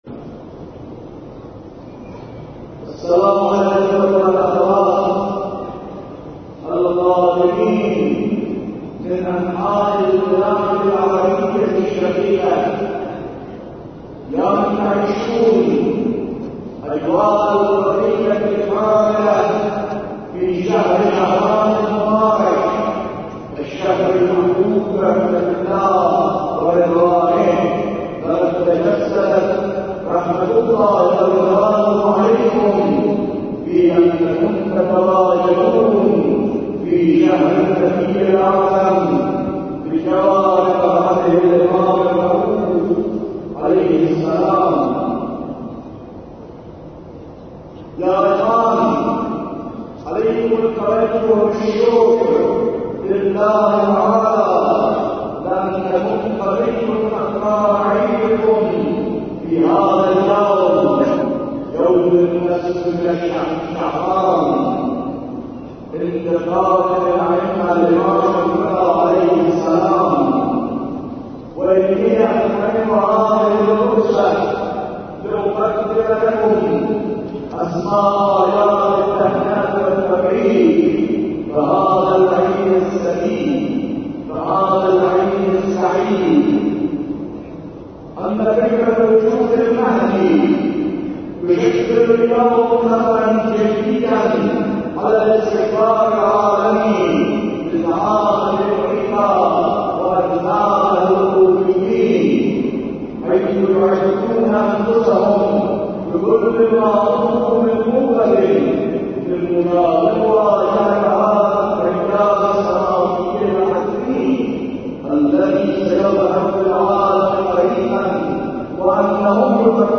خطبه عربی 23 خرداد.mp3
خطبه-عربی-23-خرداد.mp3